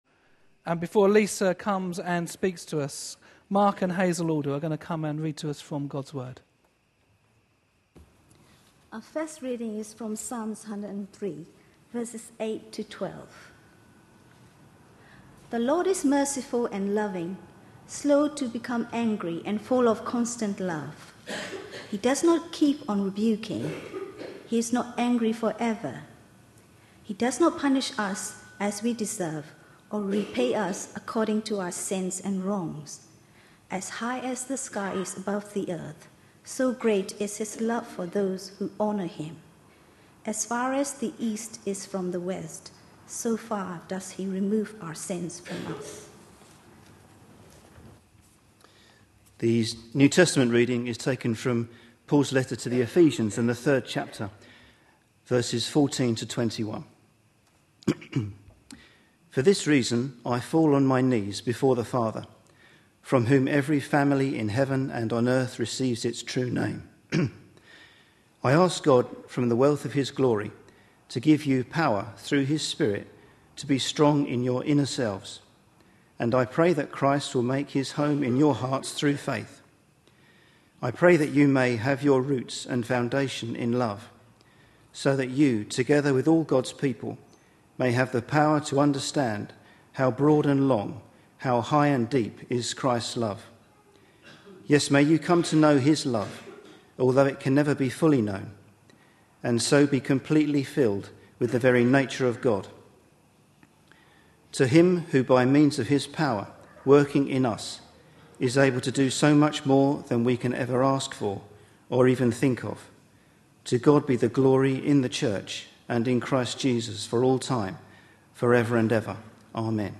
A sermon preached on 22nd January, 2012, as part of our Looking For Love (10am Series) series.